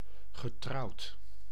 Ääntäminen
IPA: /ma.ʁje/